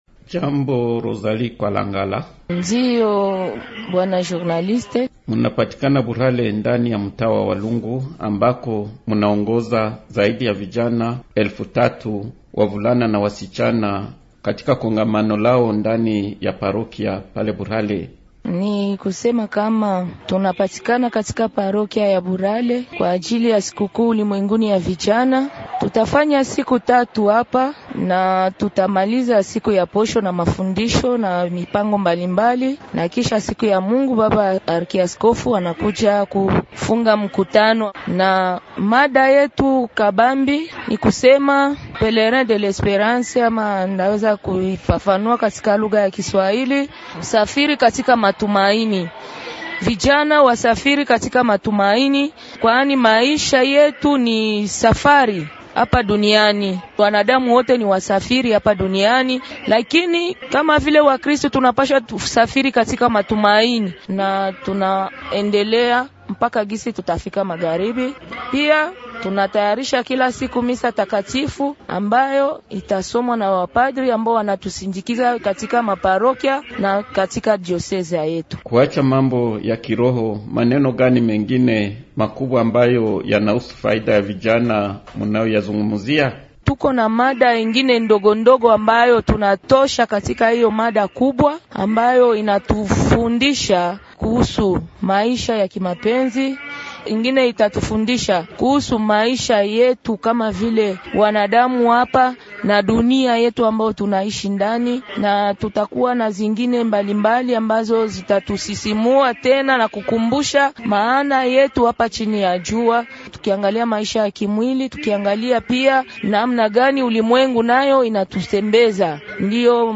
L'invité swahili, Émissions / prévisions météorologiques, éviter des accidents de navigation sur le lac Albert., la protection civile